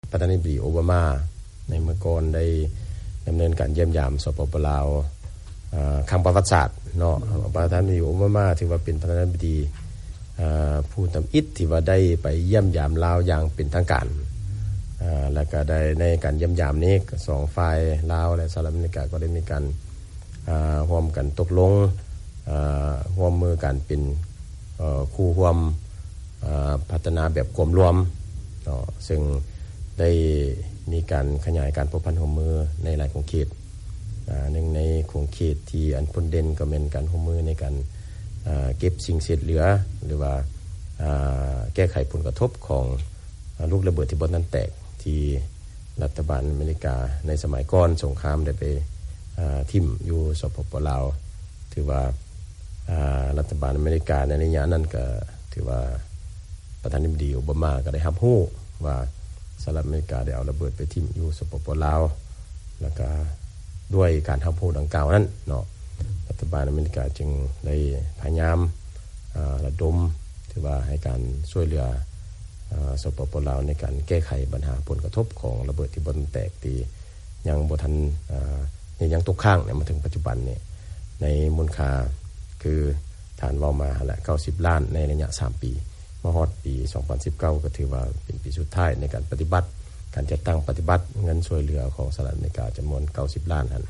ການສຳພາດພິເສດ ກັບ ລັດຖະມົນຕີ ກະຊວງການຕ່າງປະເທດ ທ່ານສະເຫຼີມໄຊ ກົມມະສິດ-4